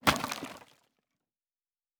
Fantasy Interface Sounds
Wood 09.wav